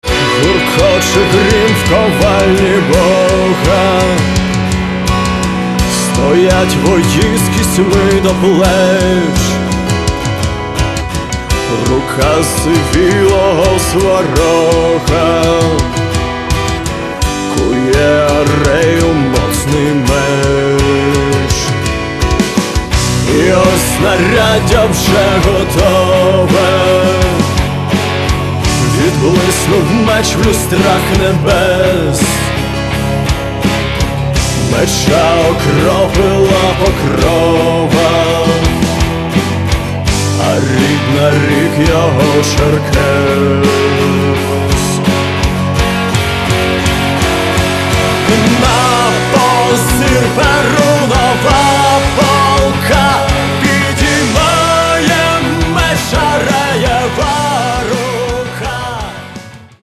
Каталог -> Рок и альтернатива -> Энергичный рок